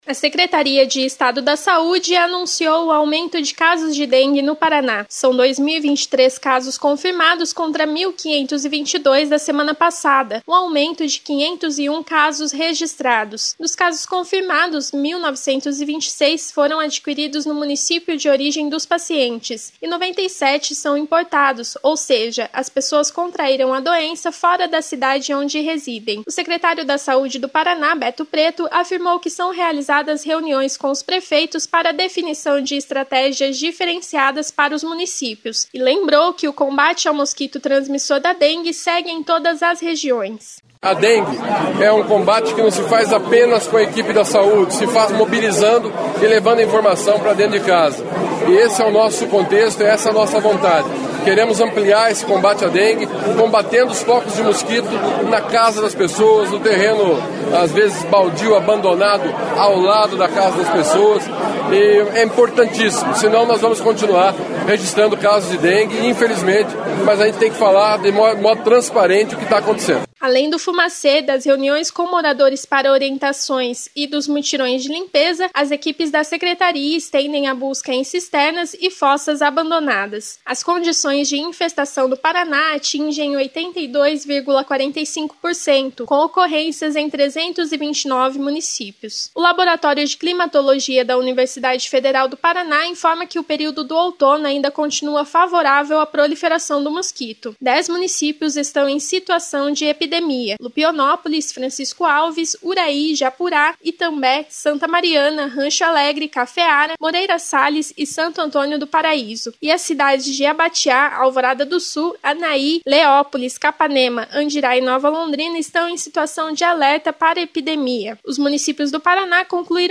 Essa e outras informações na programação da Rádio Cultura AM 930